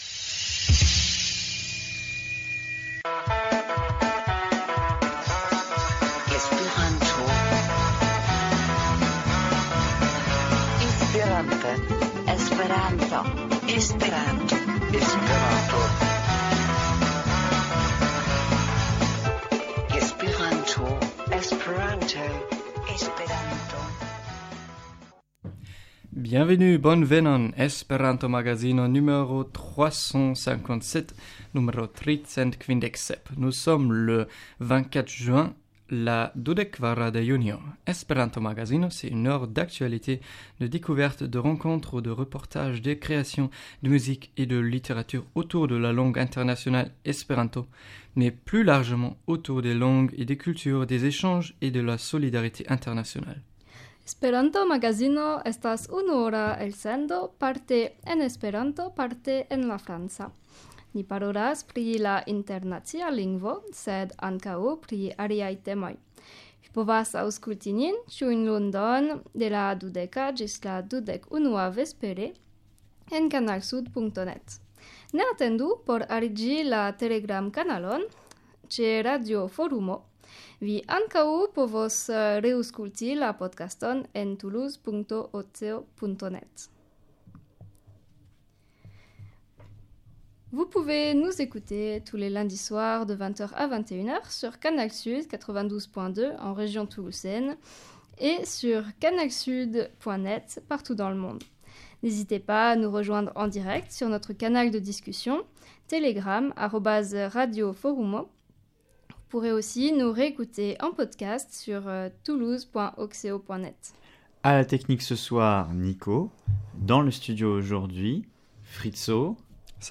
Esperanto-Magazino est l’émission hebdomadaire sur l’espéranto à Toulouse (avec de la musique en espéranto, des annonces, des petits reportages, et des chroniques… ).